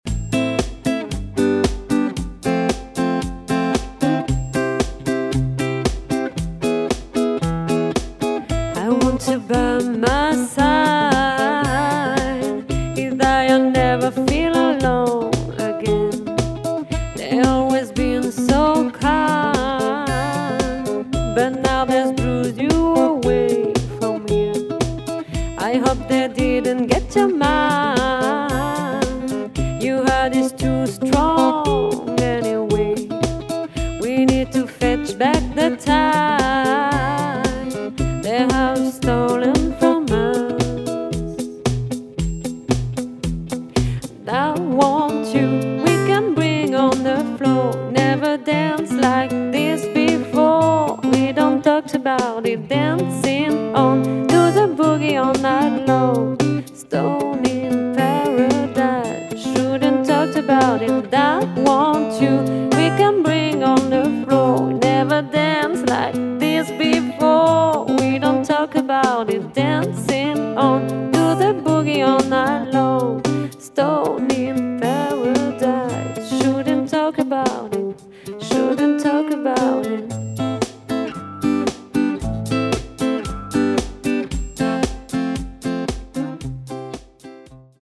un duo folk